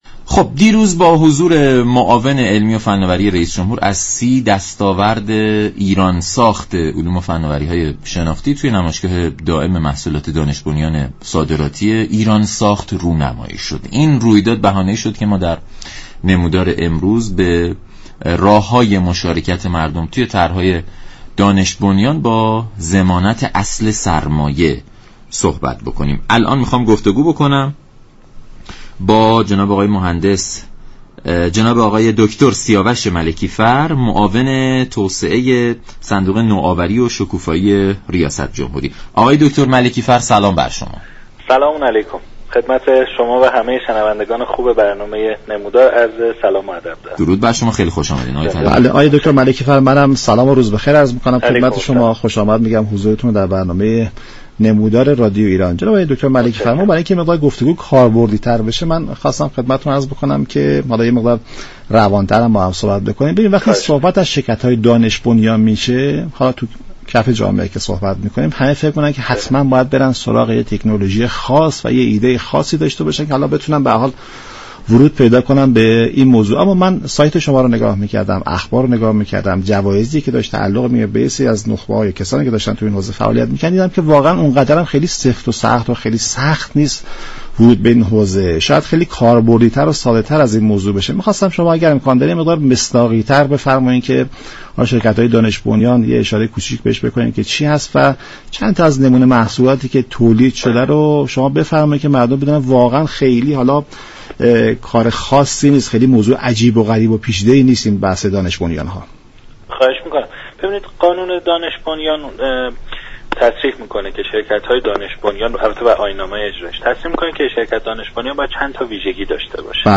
معاون توسعه صندوق نوآوری و شكوفایی ریاست جمهوری گفت: تمامی 6 هزار و 500 شركت دانش بنیان فعال در ایران هم اكنون نمونه ای از یك محصول را تولید كرده اند.